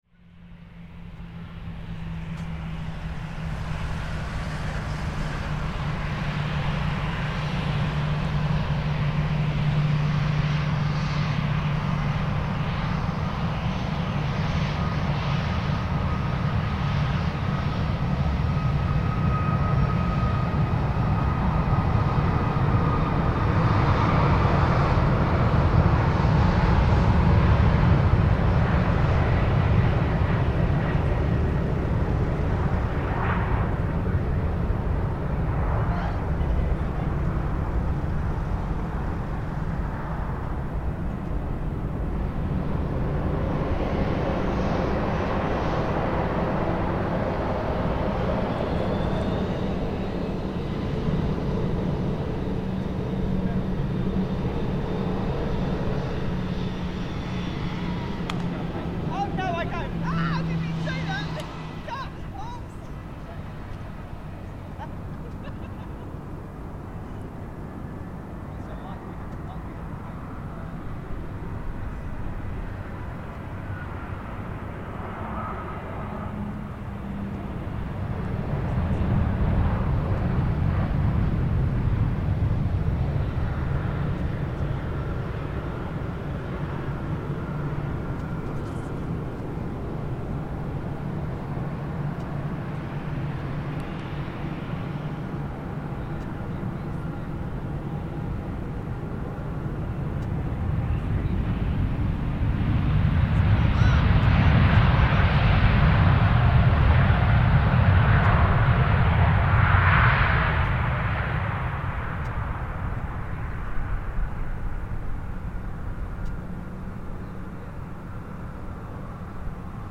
Next to the runway, Gatwick Airport
Recording of aircraft taxiing, taking off and landing at Gatwick Airport, London, recorded from extremely close to the northern runway - loud, clear aircraft sounds.